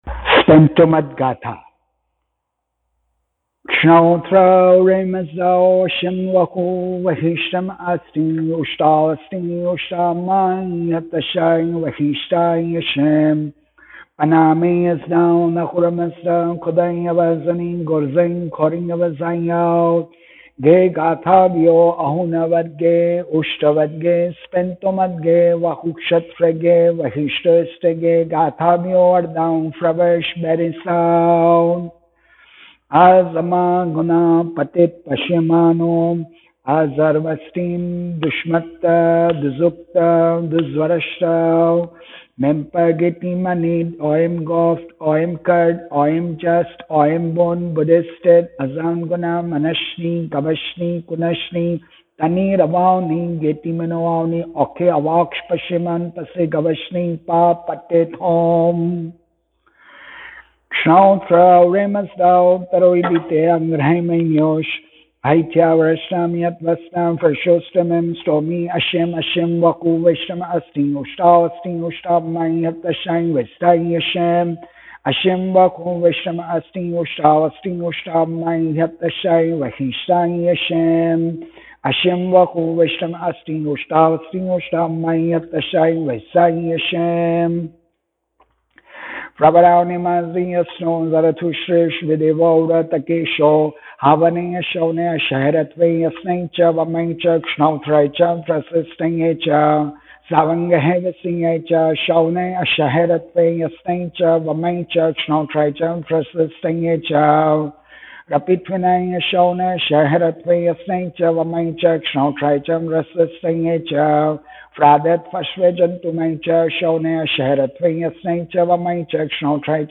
An Educative brief talk on the importance of Praying together and how to lead a Righteous life without being self righteous/dogmatic to bring about happiness to yourself, ancestors and to your children & future progeny